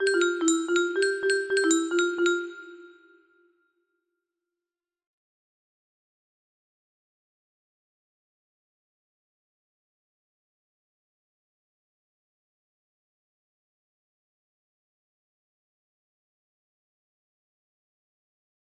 Xptmxm music box melody